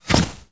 bullet_impact_snow_07.wav